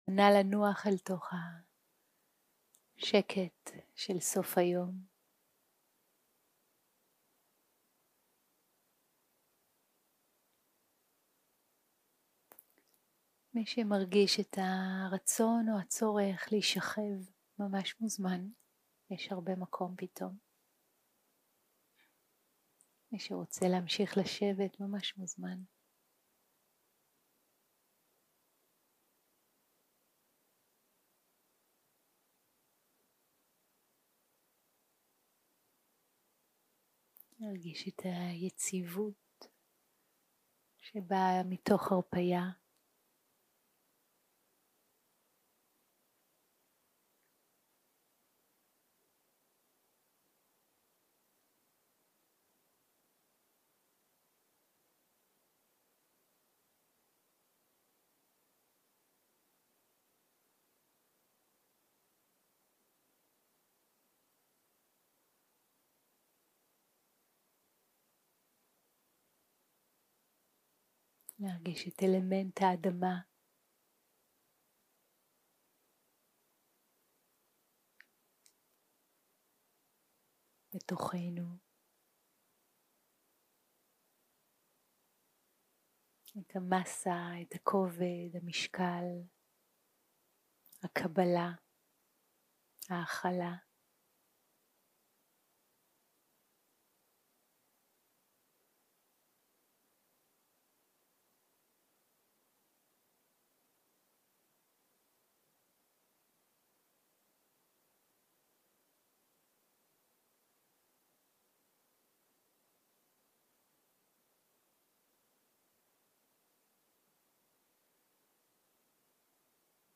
יום 4 - הקלטה 18 - ערב - מדיטציה מונחית - בחרי בדרך הלב